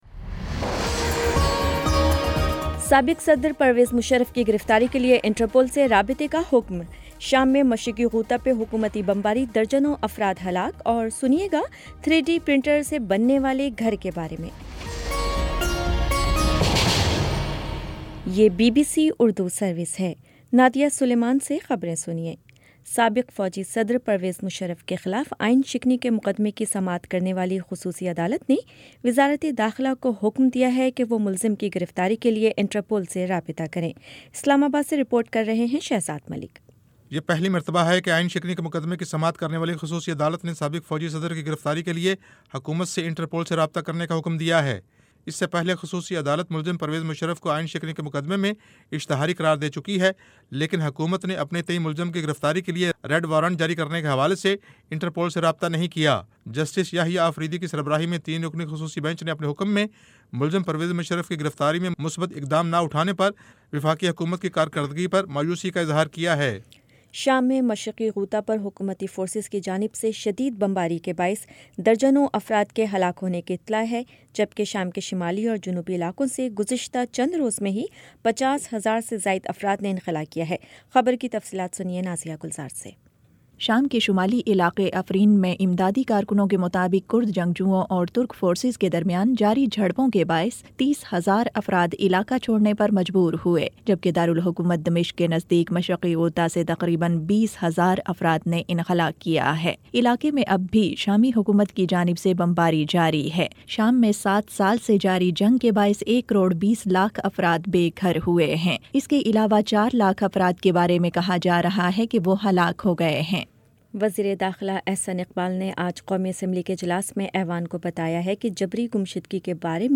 مارچ 16 : شام سات بجے کا نیوز بُلیٹن
دس منٹ کا نیوز بُلیٹن روزانہ پاکستانی وقت کے مطابق شام 5 بجے، 6 بجے اور پھر 7 بجے۔